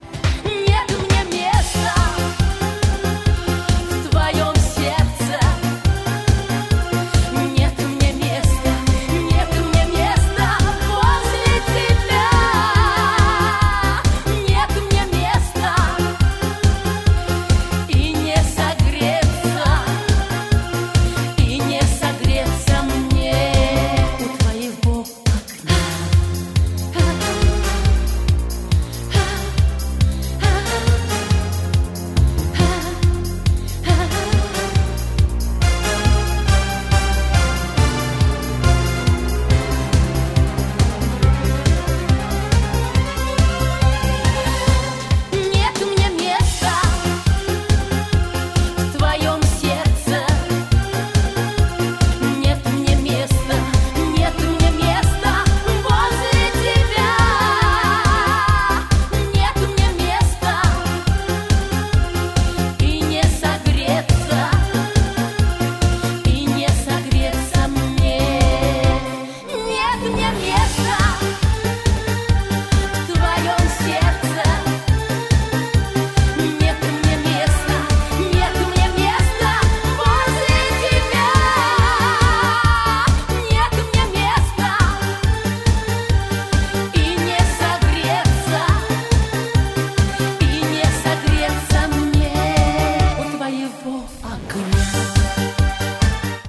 • Качество: 128, Stereo
поп
громкие
грустные
эстрадные